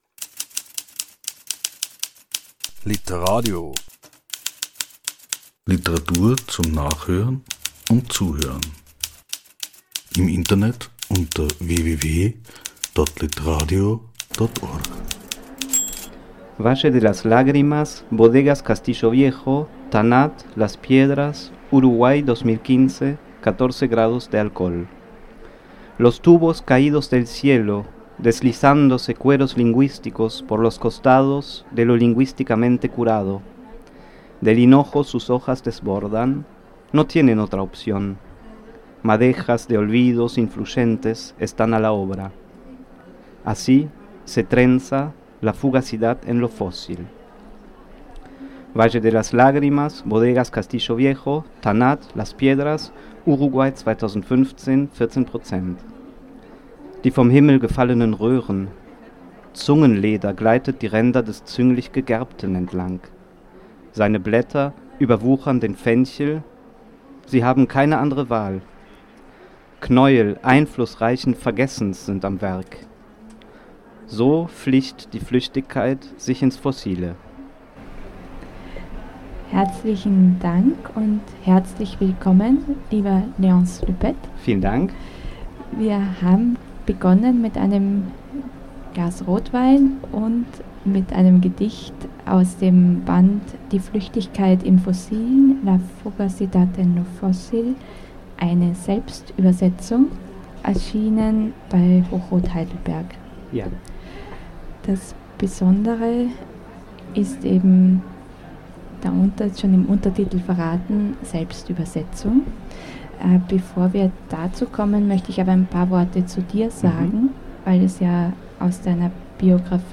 Der Autor und Übersetzer im Gespräch.